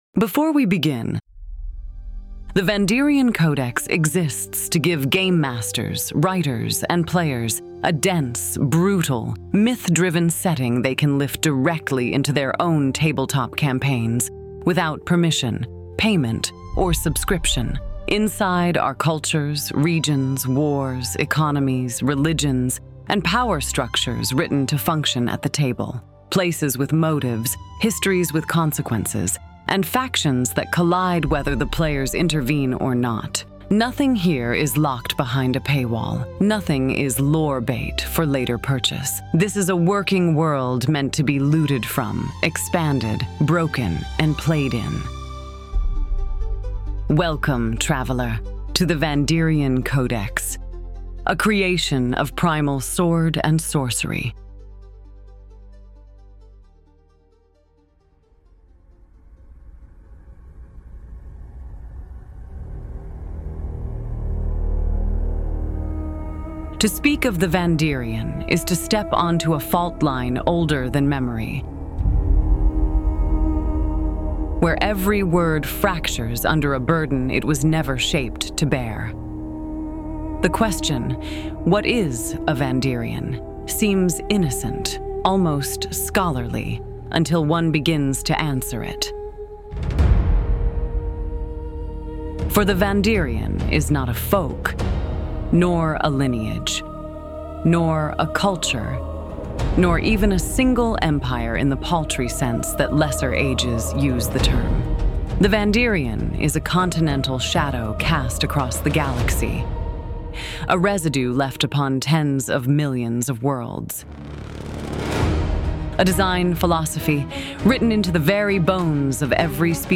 The-Vandyrian-Codex-Book-I-Primer-AudiosbookCOMPLETE.mp3